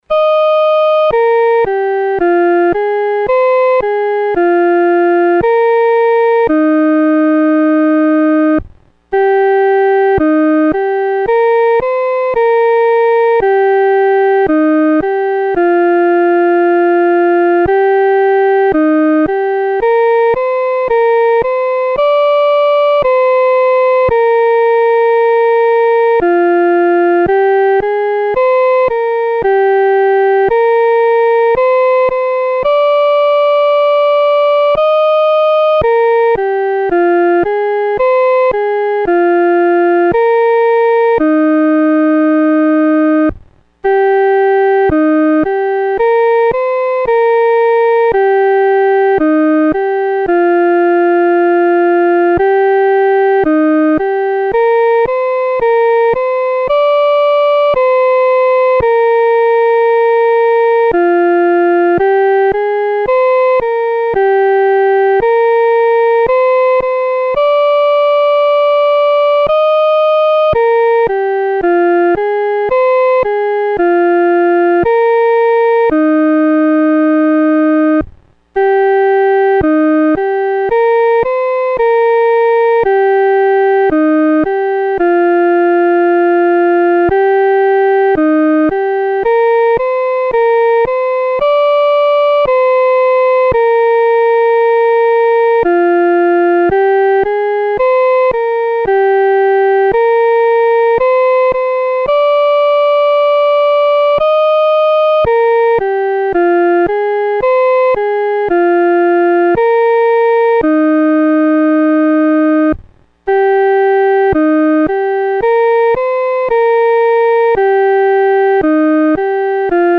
独奏（第一声）
求主掰开生命之饼-独奏（第一声）.mp3